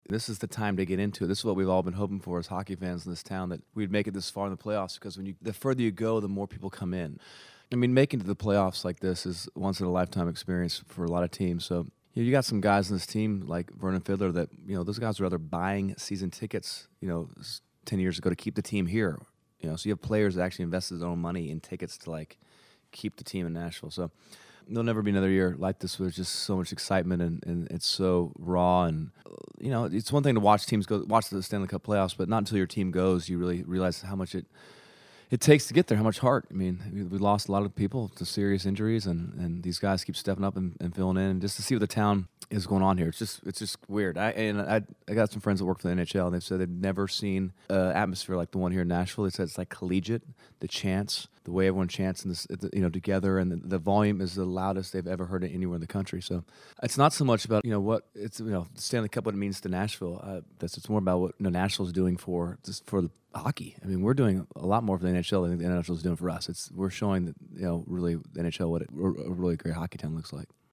Audio / Dierks Bentley talks about excitement Nashville is feeling for their NHL Predators during the Stanley Cup playoff run.